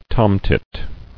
[tom·tit]